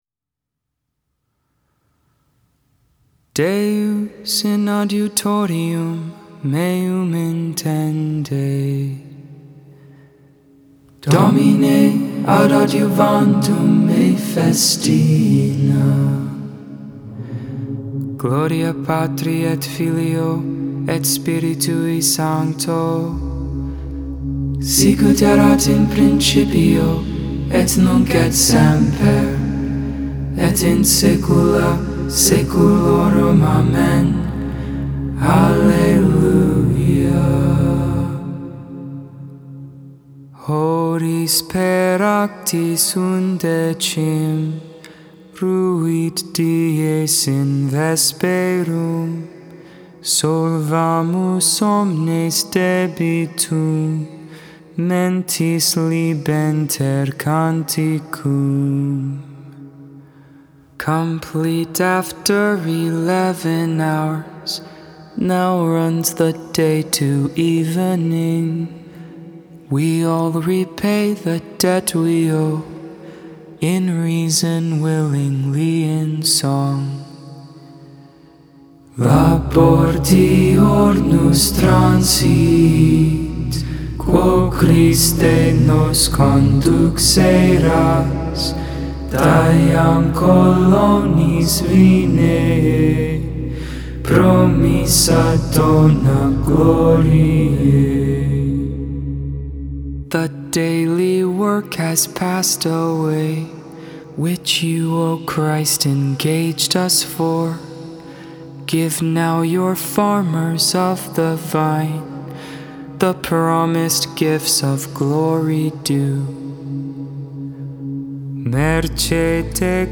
Mozarbic Hymn
(tone 6)
The Lord's Prayer Concluding Prayers Salve Regina (Gregorian) The Liturgy of the Hours (Four Vol